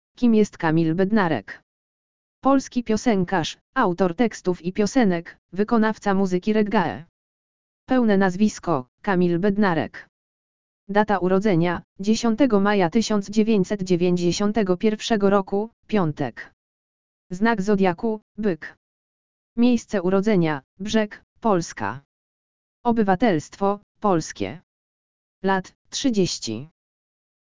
audio_lektor_urodziny_kamila_bednarka.mp3